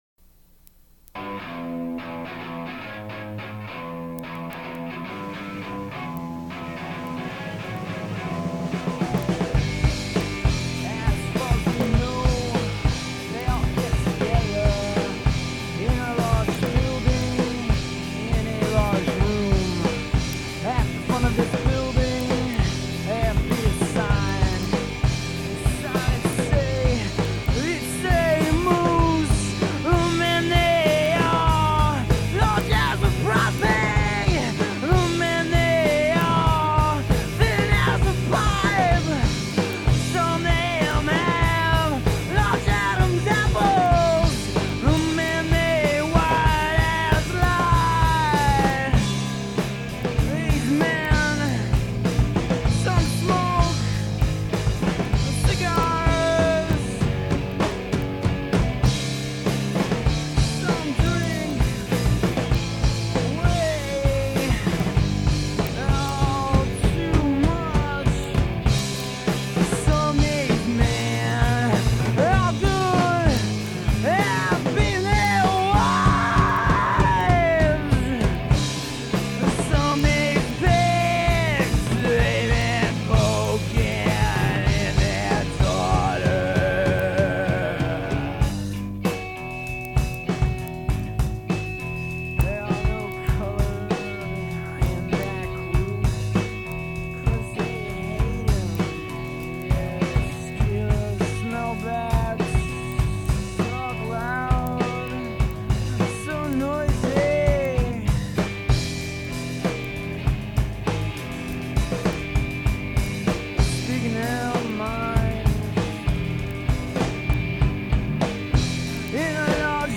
brutal, bone-rattling rock and roll
guitar
bass